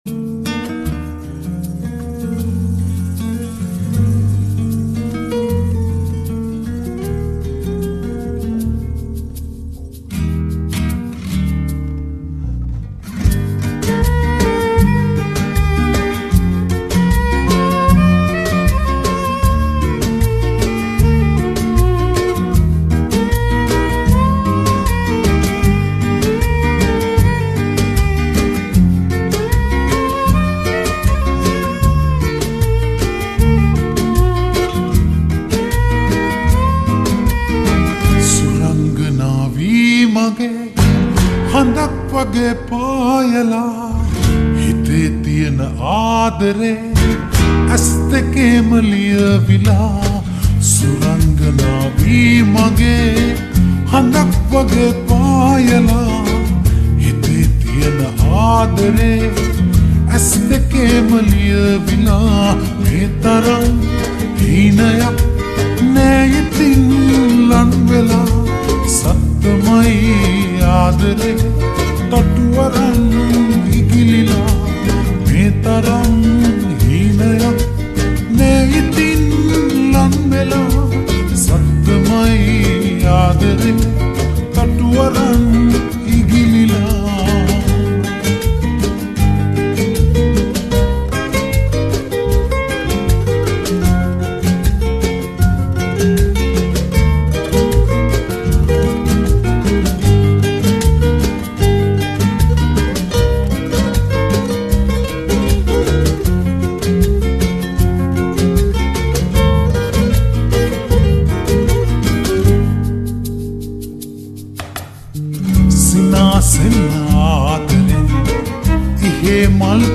slowed+reverb